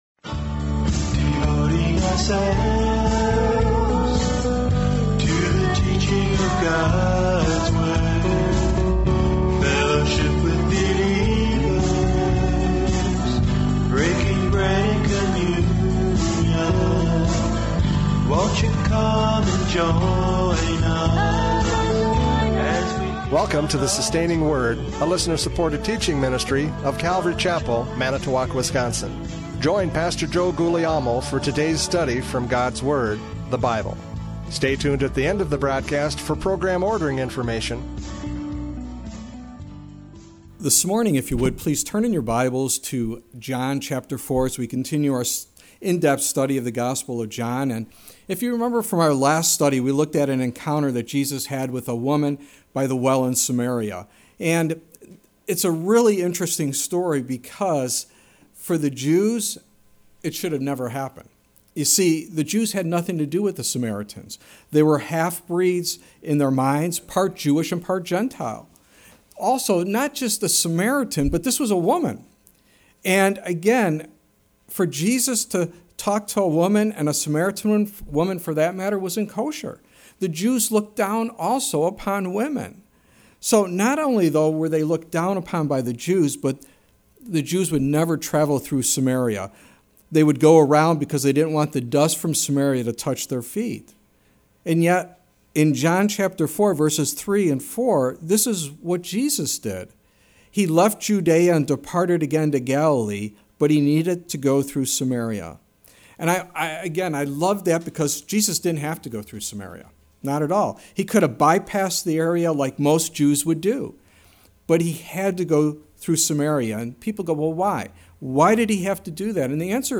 John 4:11-26 Service Type: Radio Programs « John 4:1-10 A Heart That Is Open!